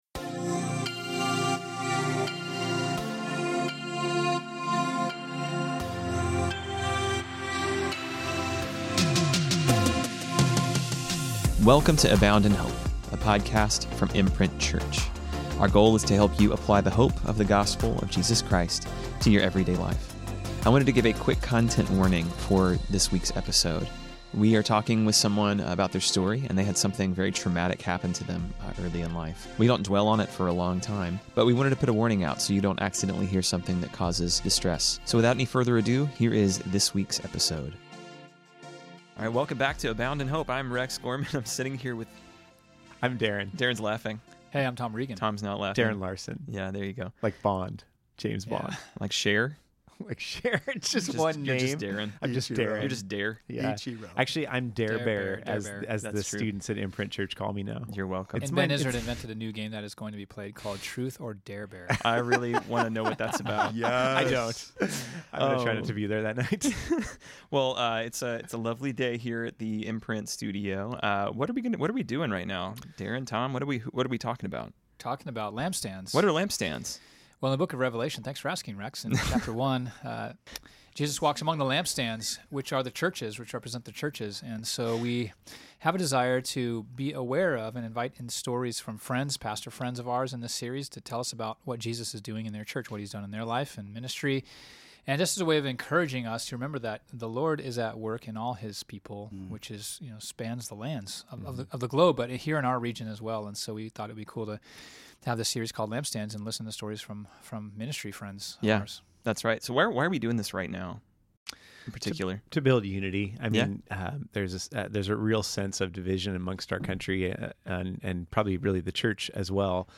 We also get a small glimpse into his beatboxing skills.